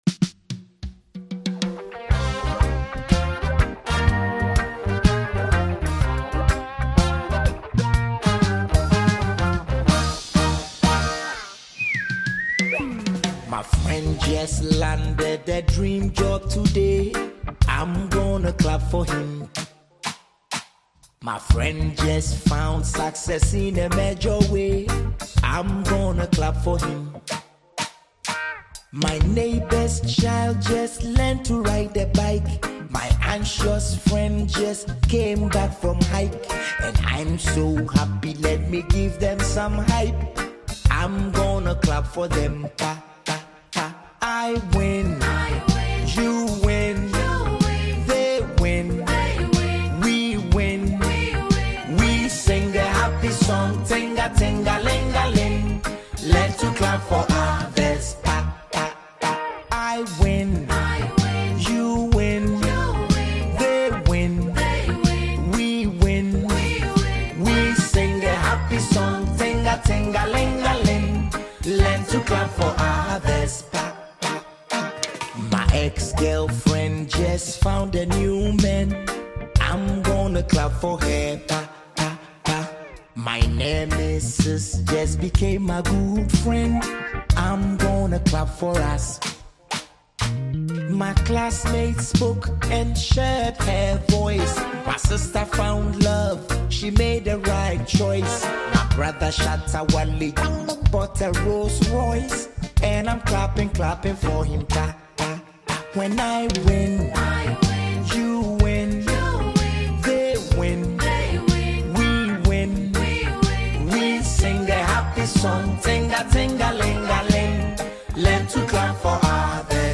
• Genre: Afrobeat / Highlife